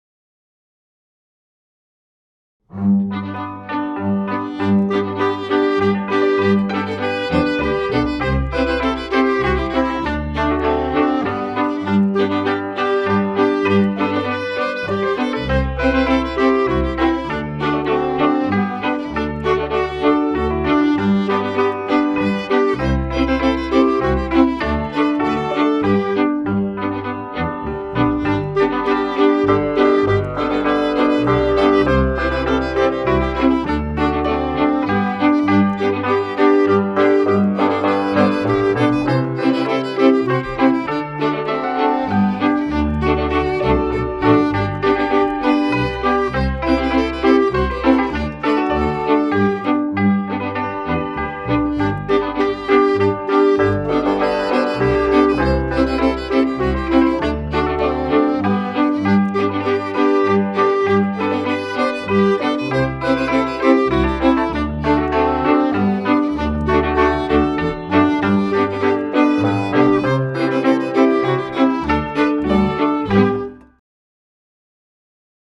Cílem soutěže Píseň ožívá je vrátit lidovou píseň zpět do života, aby nebyla jen dědictvím minulosti, ale stala se živým prostorem pro vlastní tvorbu.
• Sloky budou nazpívány do hudebního doprovodu cimbálové muziky.